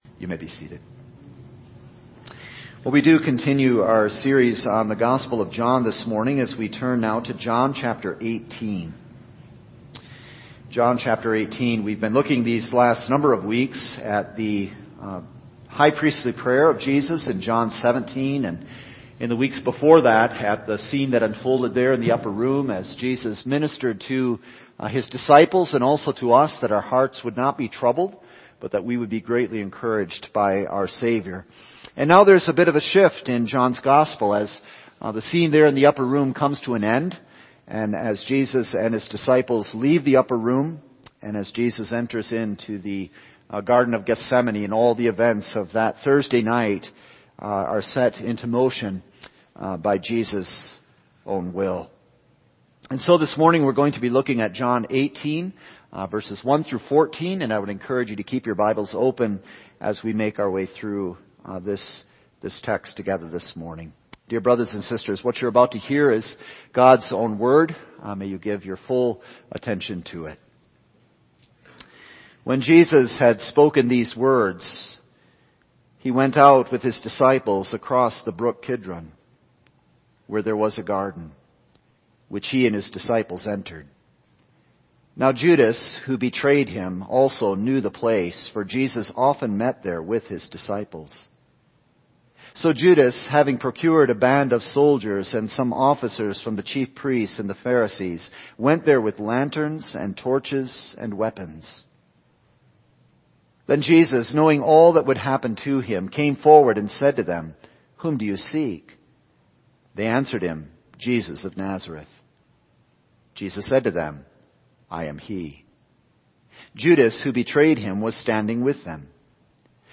All Sermons The Garden Made a Grave June 12